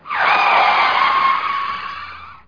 SKIDS.mp3